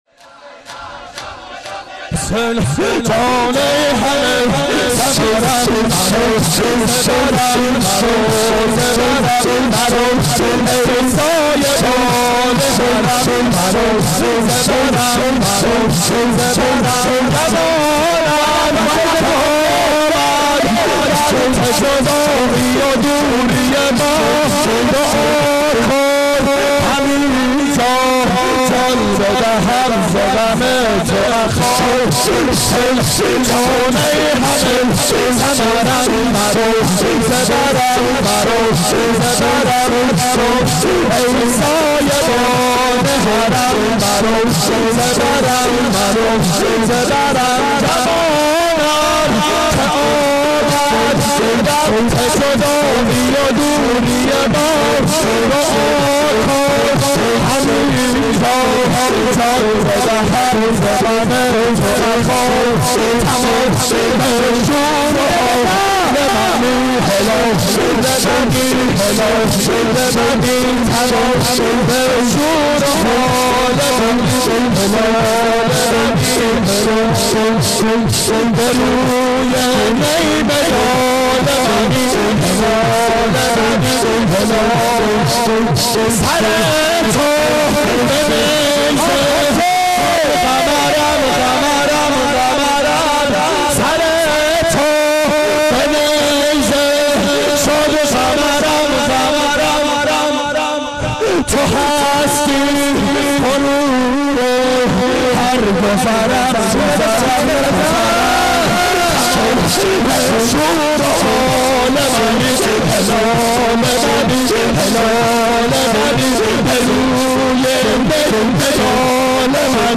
شب سوم محرم 88 گلزار شهدای شهر اژیه